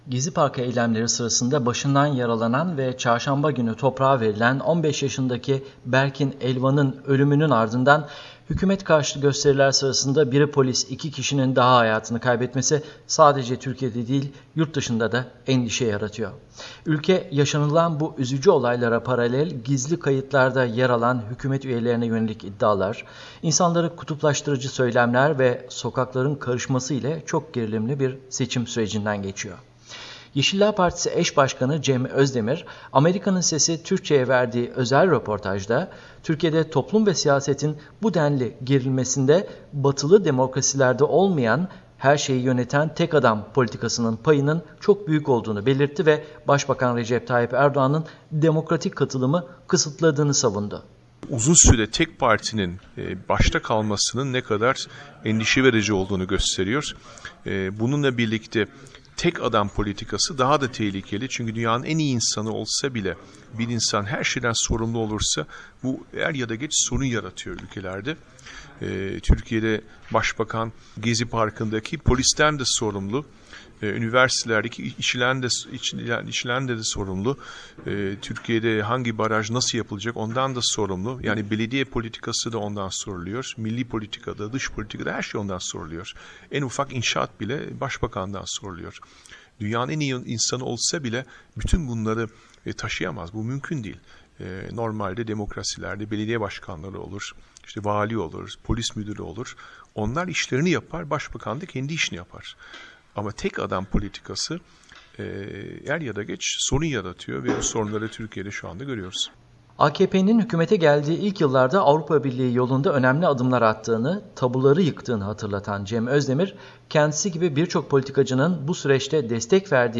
Cem Özdemir'le Söyleşi